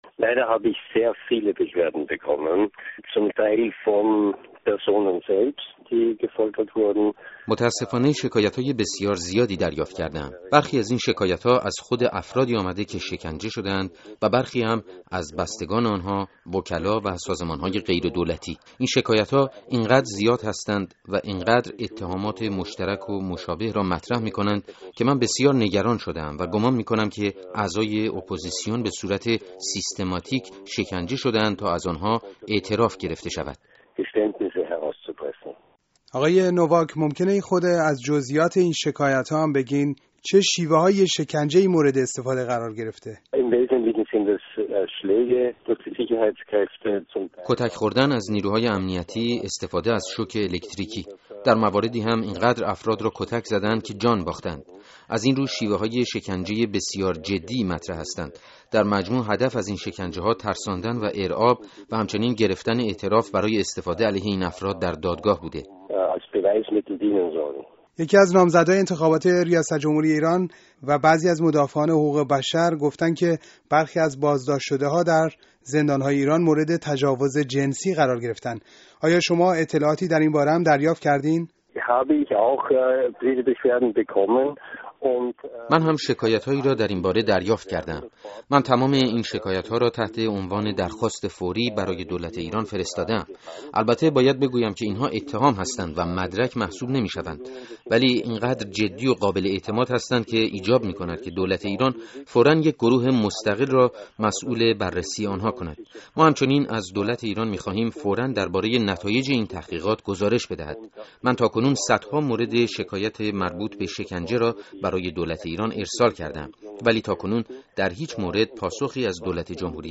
گفت‌وگو با مانفرد نوواک، گزارشگر ویژه سازمان ملل درباره شکنجه